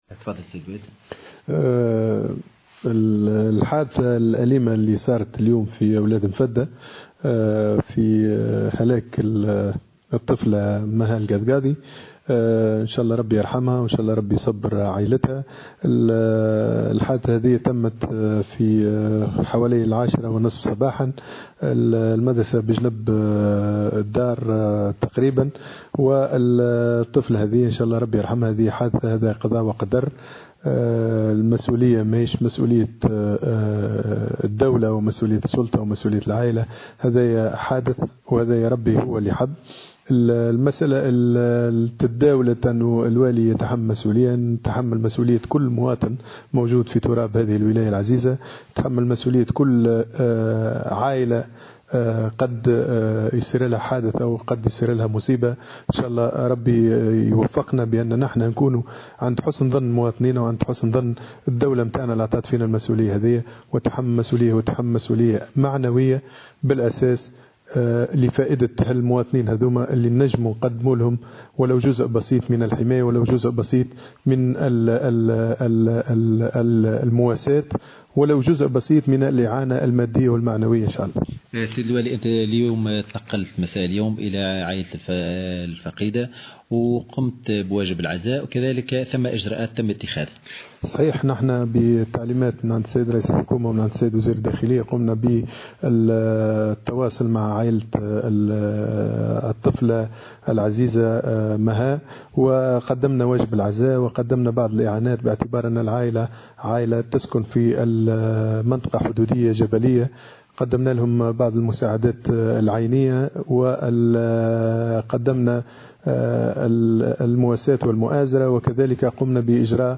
أكد والي جندوبة علي المرموري في تصريح لمراسل الجوهرة أف أم، إن المسؤولية عن حادثة وفاة الطفلة التي جرفتها مياه الأمطار في منطقة "وادي مفدّة" من معتمدية فرنانة، لا تتحمّلها الدولة أو السلطة أو العائلة، وأن الحادثة تعتبر "قضاءً وقدرا".